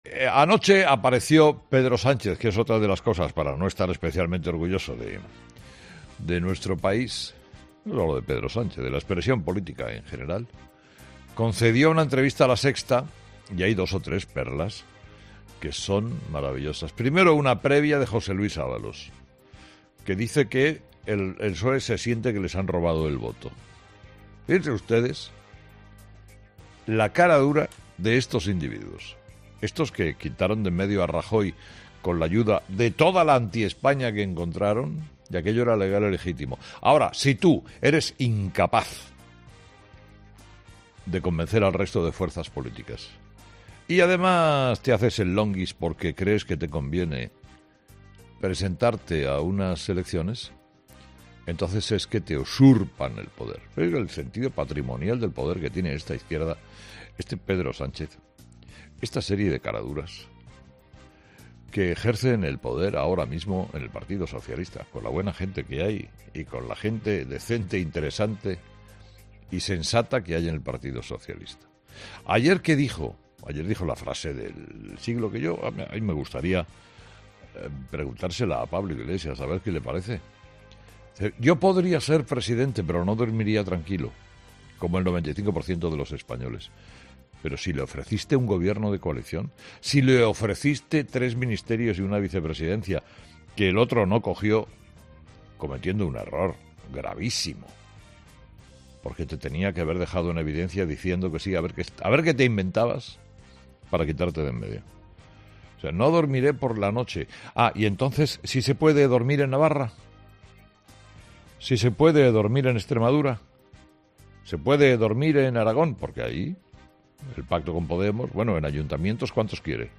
Carlos Herrera ha dedicado su monólogo de las seis de la mañana a desgranar, analizar y evidenciar las declaraciones de Pedro Sánchez anoche en su entrevista en LaSexta.